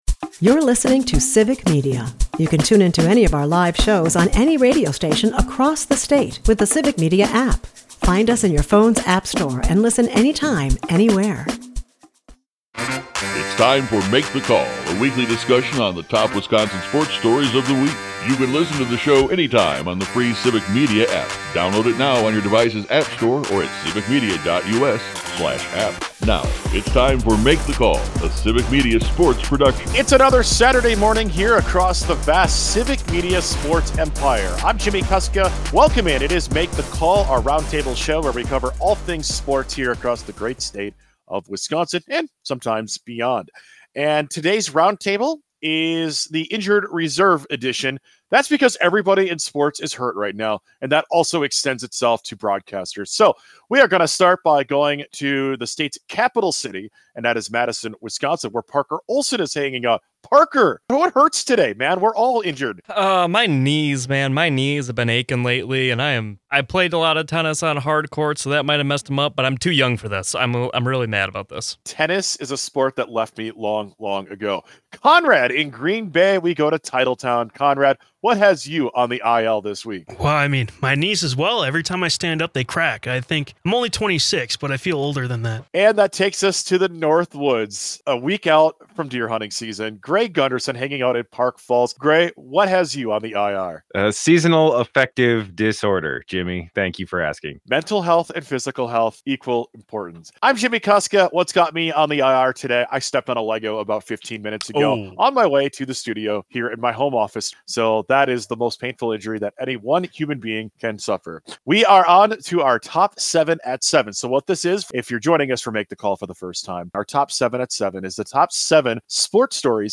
In this week’s interview segment